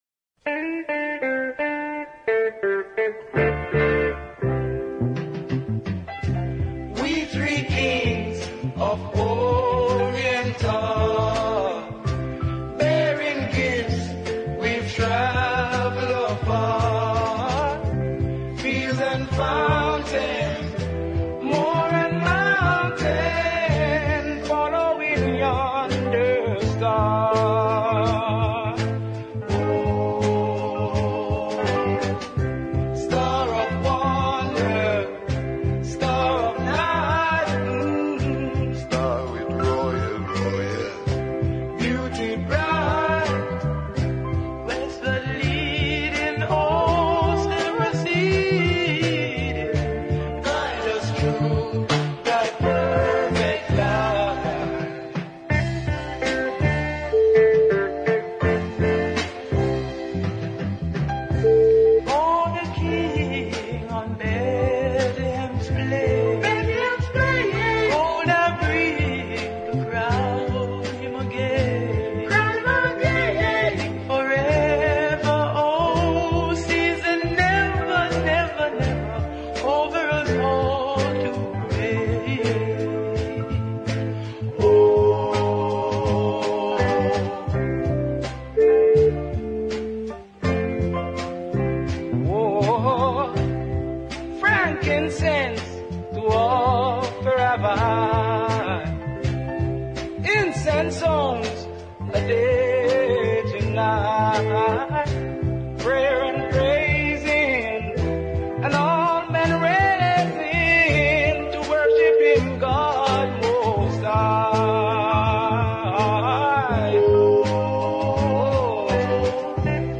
The Gaylads were one of Jamaica’s finest vocal groups, making some wonderful rocksteady music for Coxsone Dodd and Sonia Pottinger in particular.